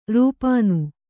♪ rūpānu